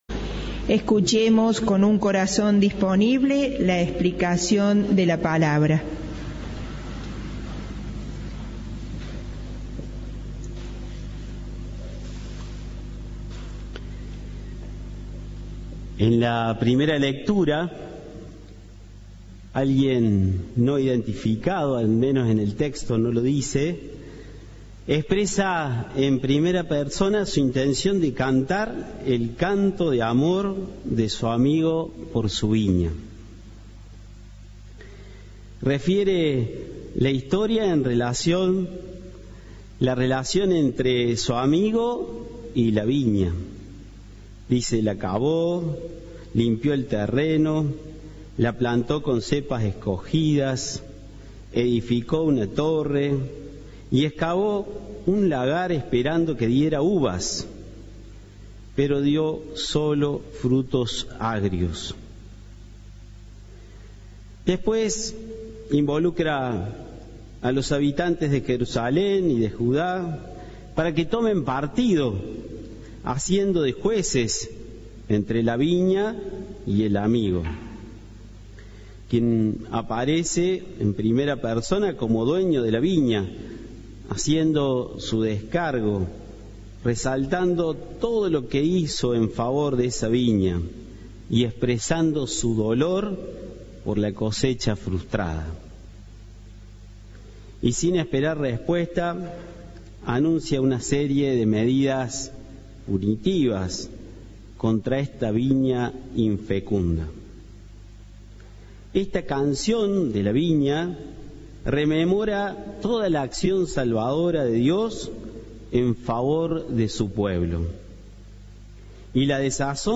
El padre Alejandro Musolino pidió tener una fe activa dedicada a la misión - Santa Misa - Cadena 3 Argentina